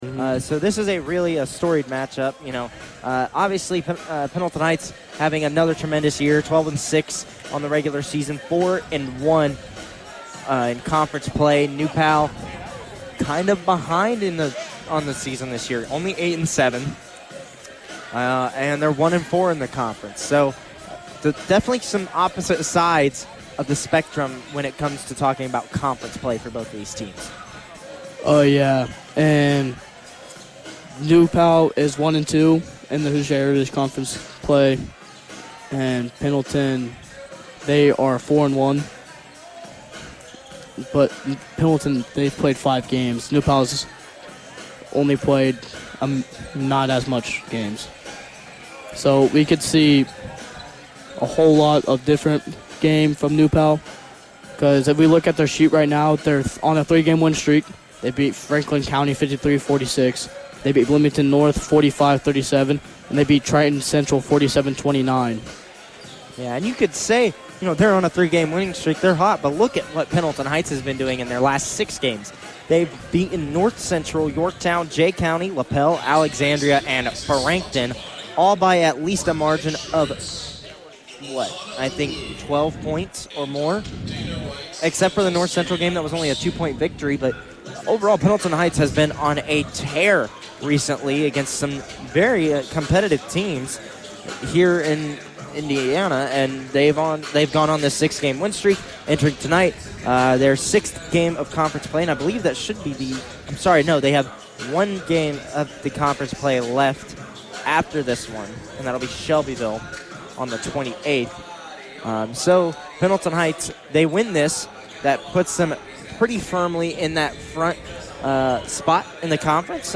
Varsity Girls Basketball Broadcast Replay Pendleton Heights vs. New Palestine 1-17-25